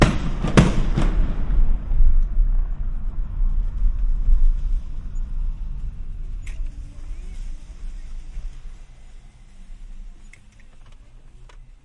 描述：在圣安娜内部的各个地方发生了2次烟花爆炸，用Roland CS10EM双耳麦克风/耳机和Zoom H4n Pro录制。没有添加后处理。
声道立体声